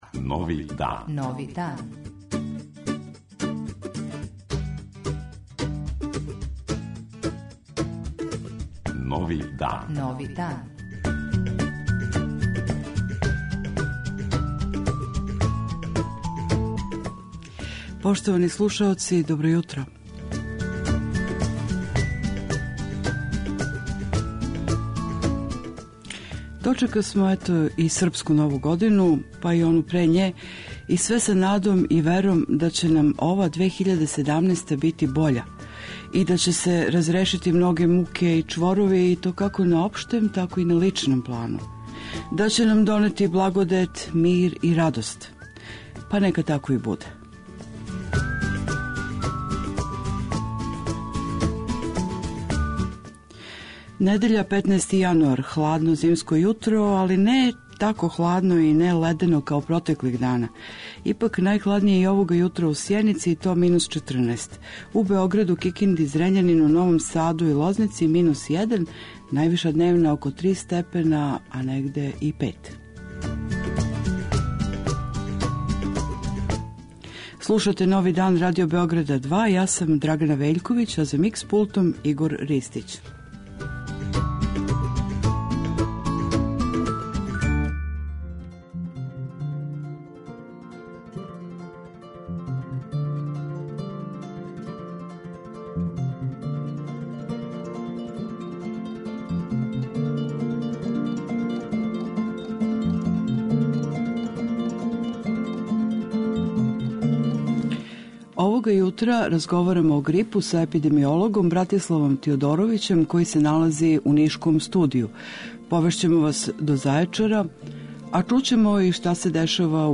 У 'Новом дану' - нове теме, редовне рубрике, добра музика...